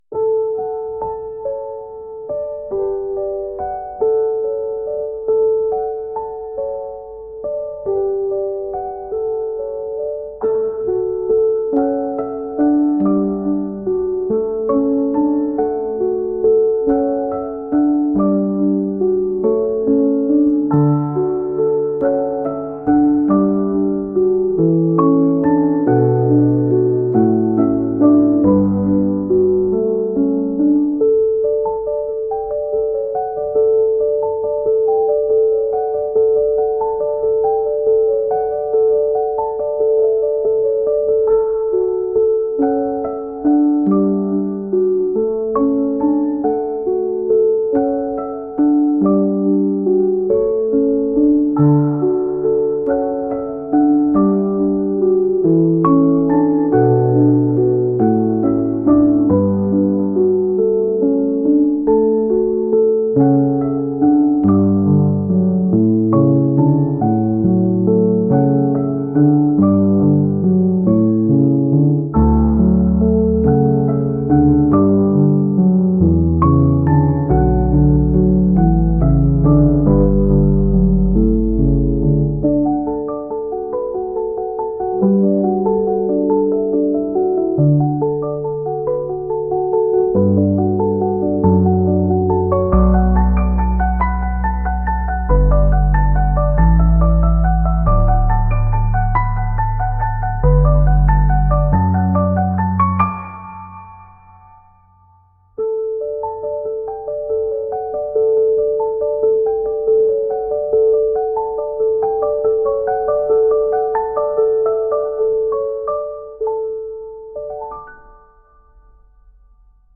水の中にいるようなピアノ曲です。